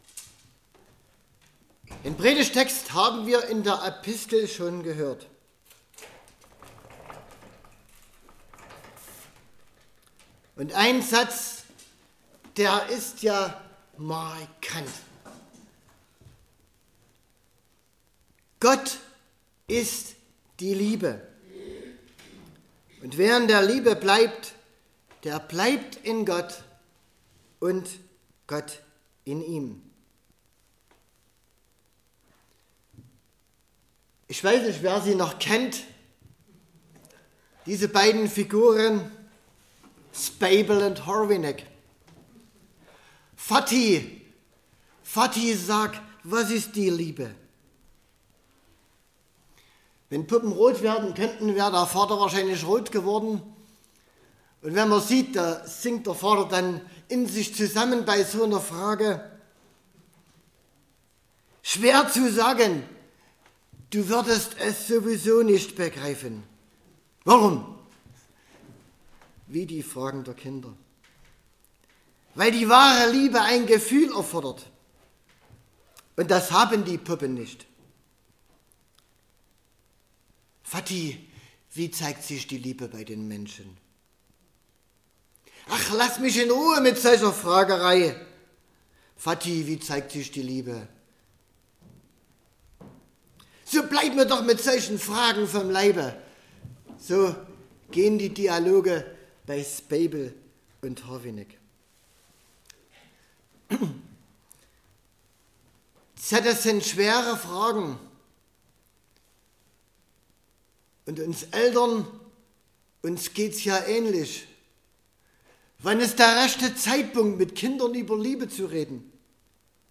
Eine Gastpredigt